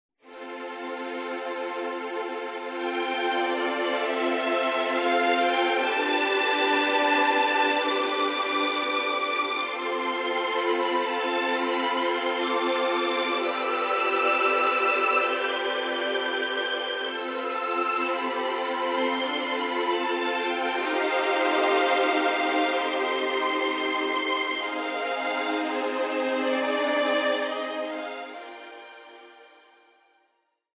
instrumental arrangement